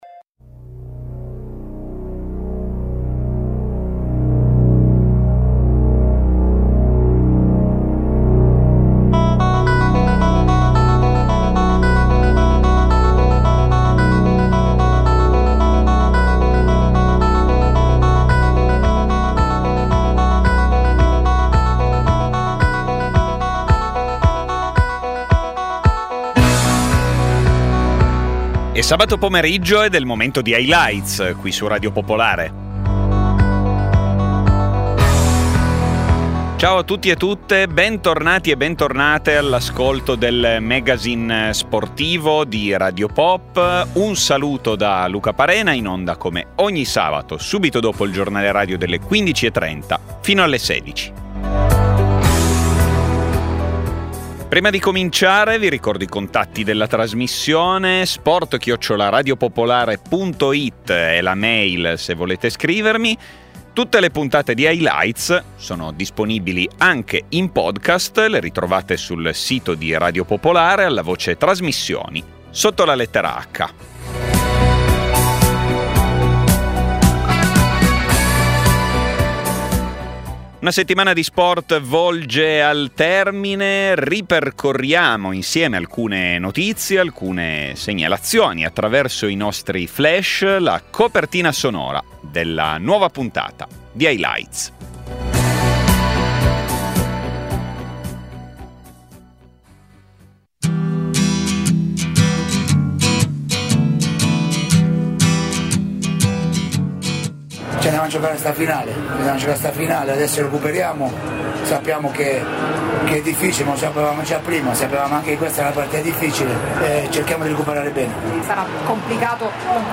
Un dialogo